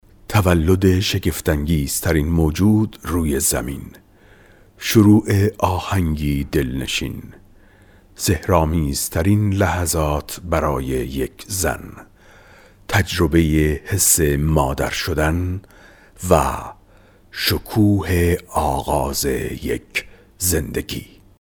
Male
Adult
Naration